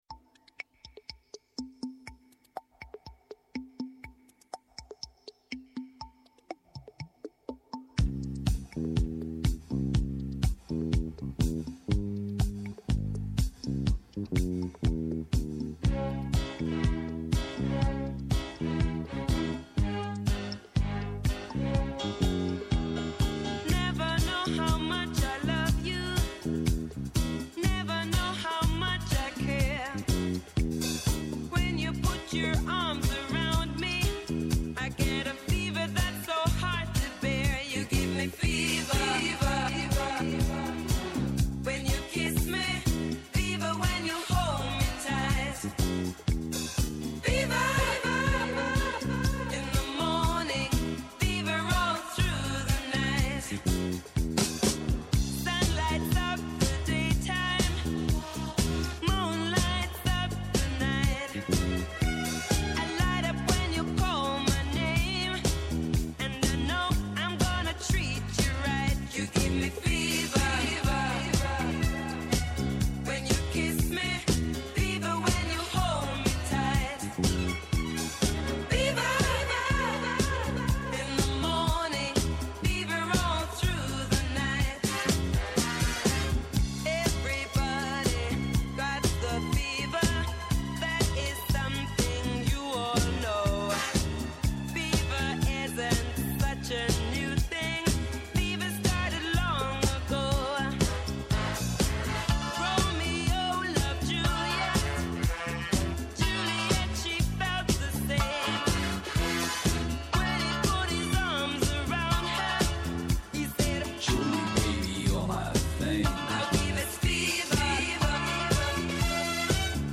Καλεσμένοι απόψε στην εκπομπή οι ηθοποιοί της παράστασης «Φωτιά και νερό»